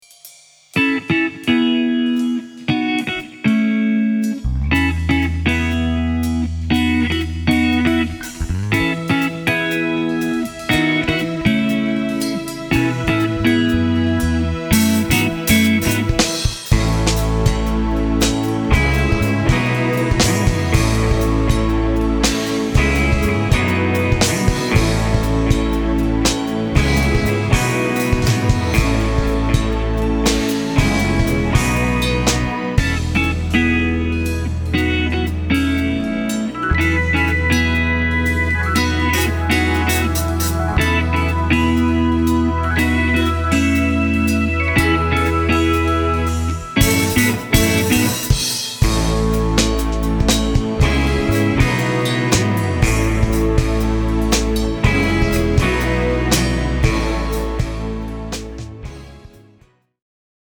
Experimental instrumental music